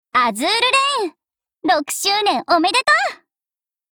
碧蓝航线/六周年登录语音